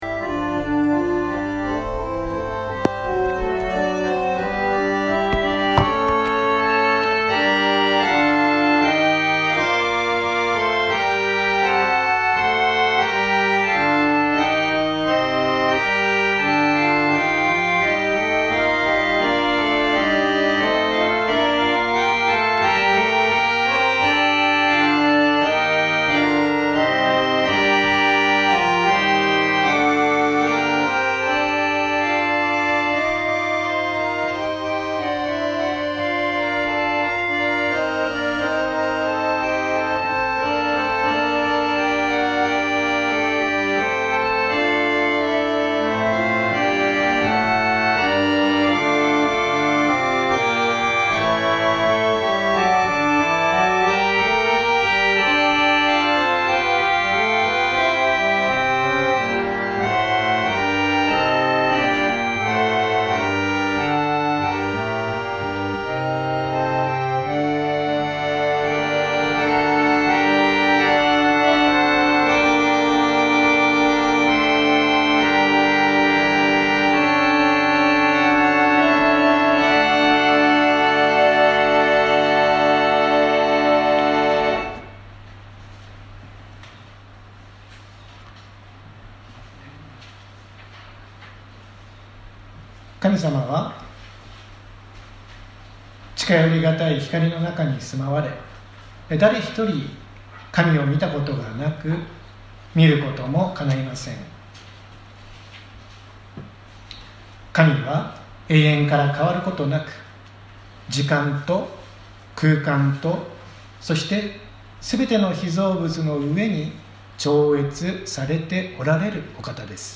私たちは毎週日曜日10時20分から12時まで神様に祈りと感謝をささげる礼拝を開いています。
音声ファイル 礼拝説教を録音した音声ファイルを公開しています。